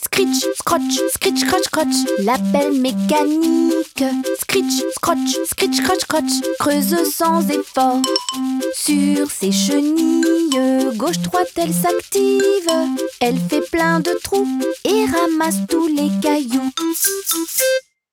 Véritable petite encyclopédie sonore, composée de plus de 100 mots à nommer et des sons à écouter.
LE-CHANTIER.mp3